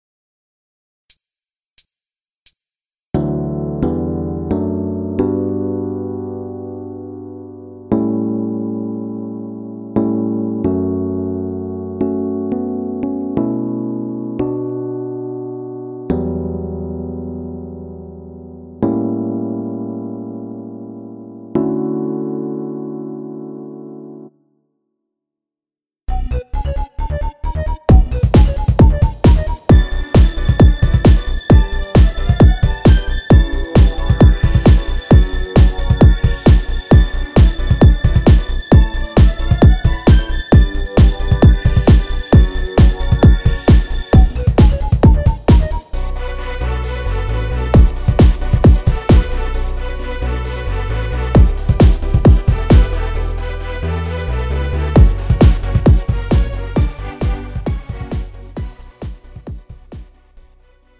음정 (-2)
장르 가요 구분 Lite MR